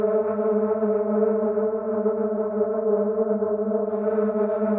SS_CreepVoxLoopA-06.wav